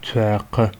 цуакъэ somewhat like Botswana